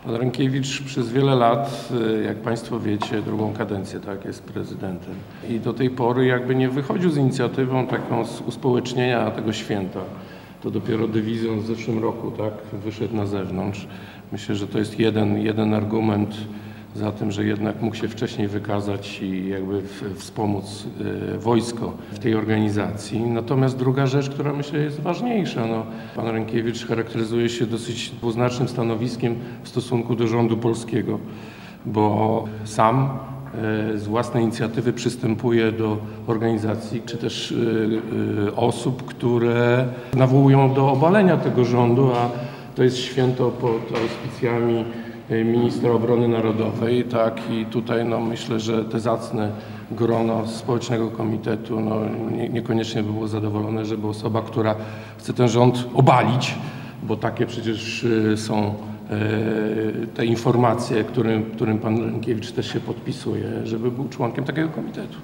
Takie stanowisko Grzegorz Mackiewicz przedstawił w środę (09.08), podczas konferencji prasowej poświęconej organizacji Święta Wojska Polskiego.